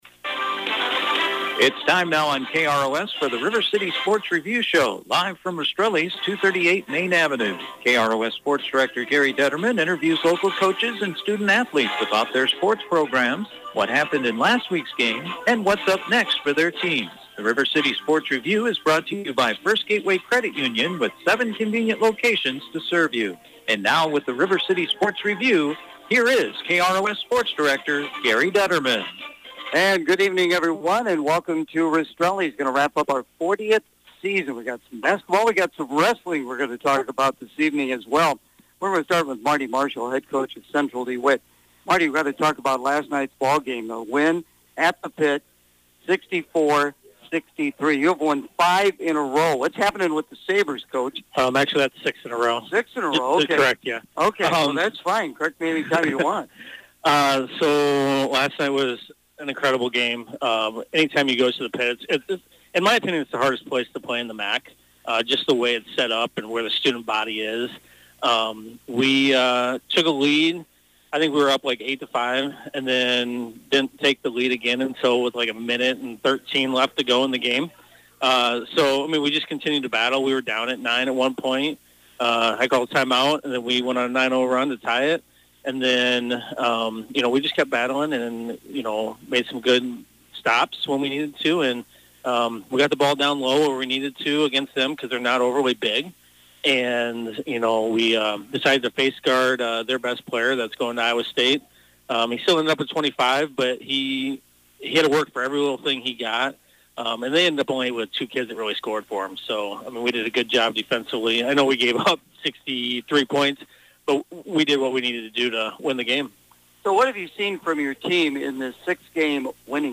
The River City Sports Review Show on Wednesday night from Rastrelli’s Restaurant
with the area coaches to preview this weekends sports action